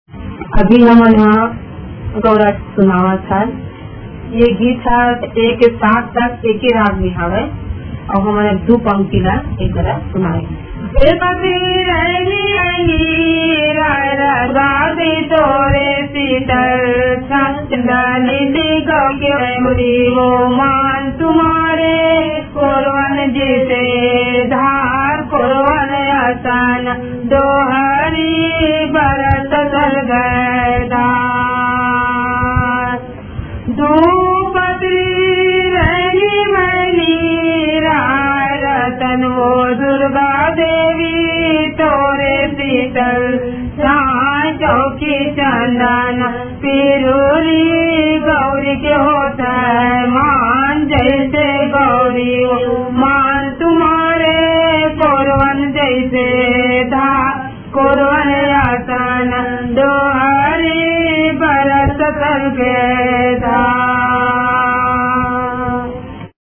Chhattisgarh
गऊरा लोक गीत सिर्फ महिलाए ही गाती हैं ।